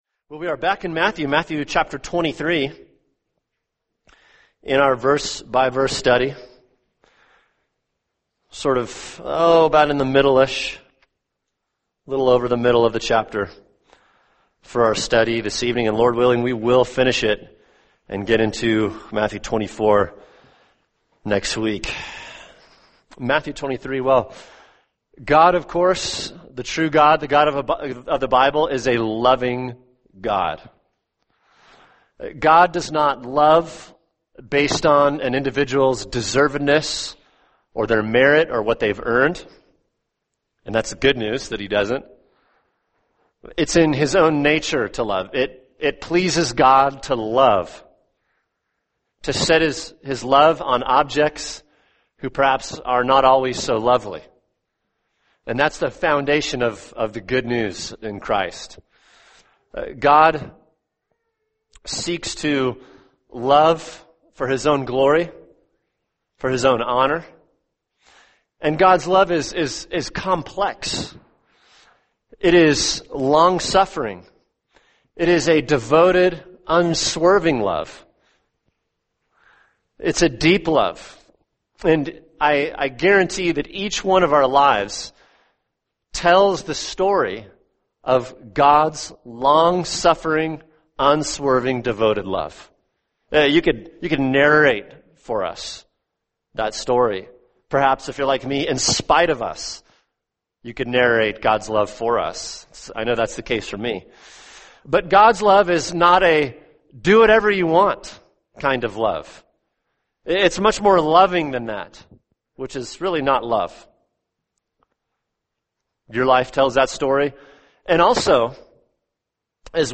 [sermon] Matthew 23:27-39 The Kind of Leader God Condemns – Part 3 | Cornerstone Church - Jackson Hole